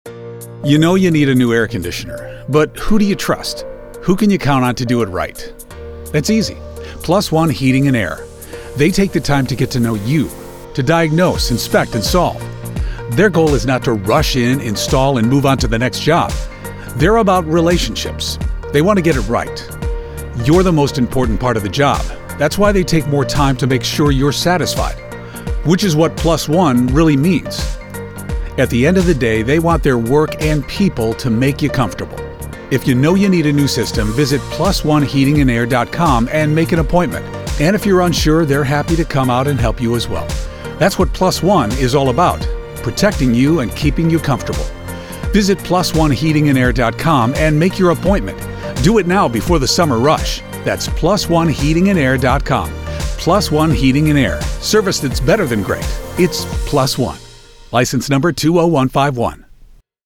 Our current commercials: